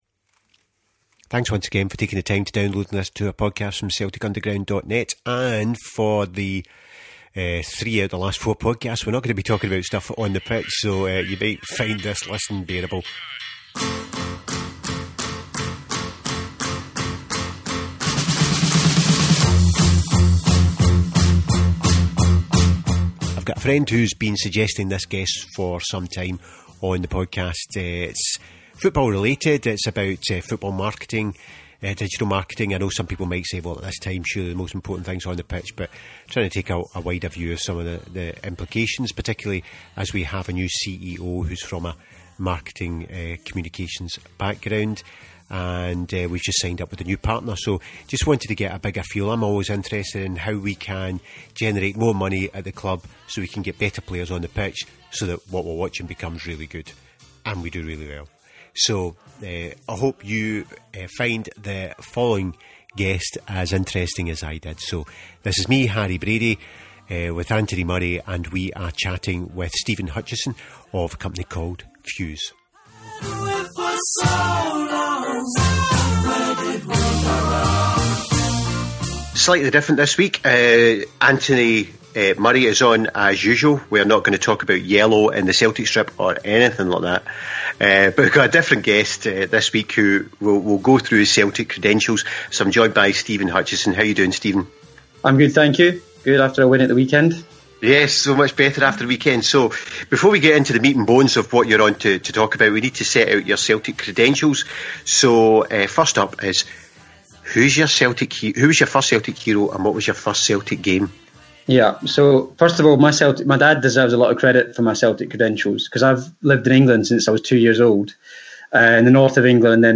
We therefore have a marketing expert talking specifically about our club and how we might get those marginal benefits that allow us to get better players on the park.